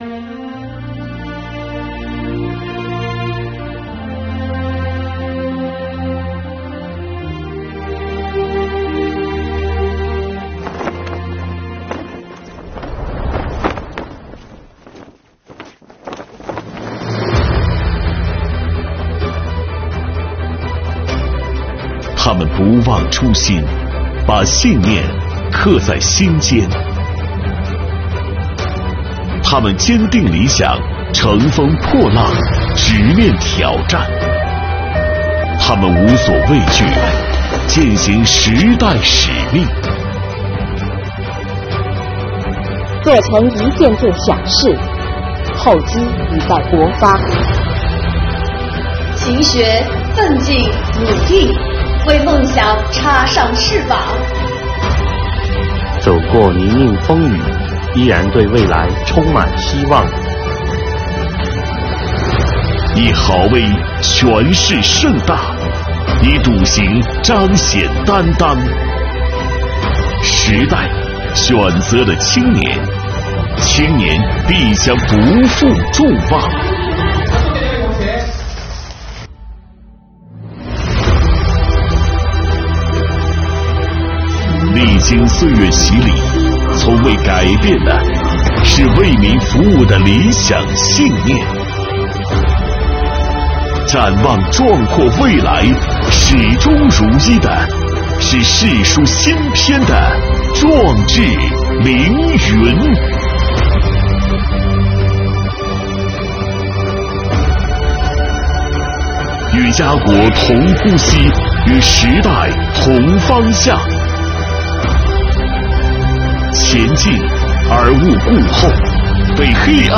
配音